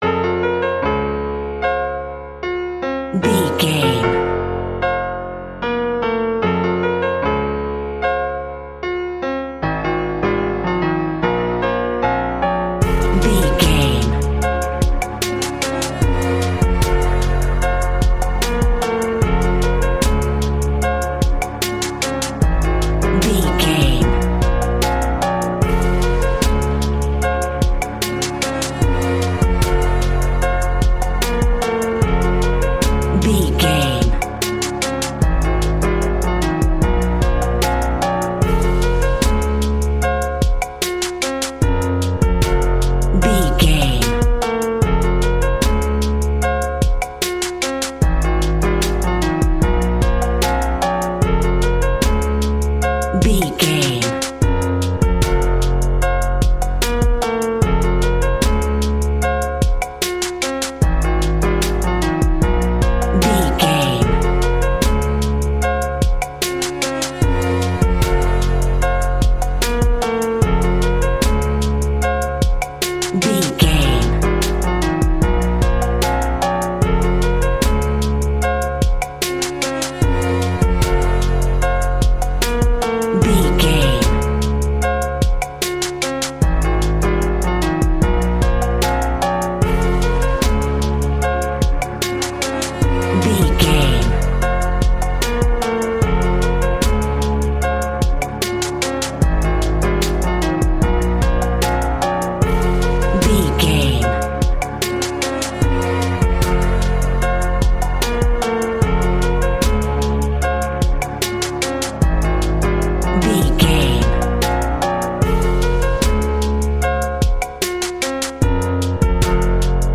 Ionian/Major
hip hop
chilled
laid back
groove
hip hop drums
hip hop synths
hip hop pads